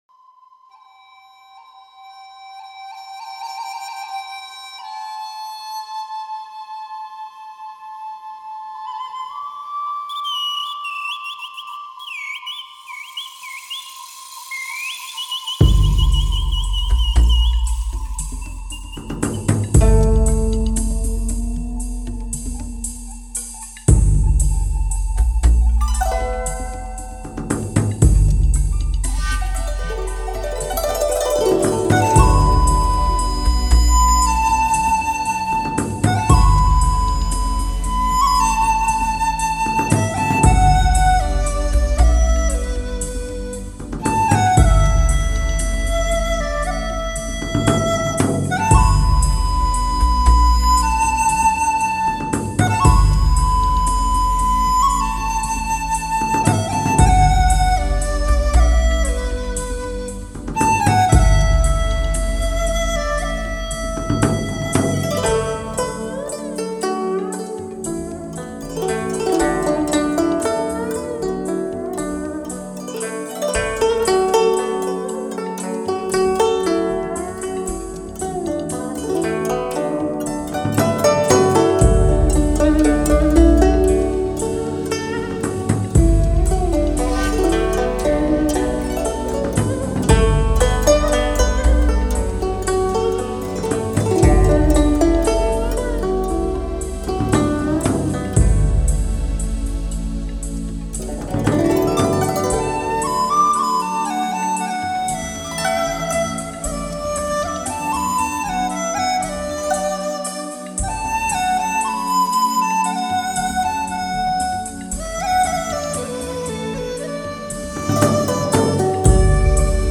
纯音乐的世界
純情樂聲 音效非凡